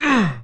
dfury_grunt1.wav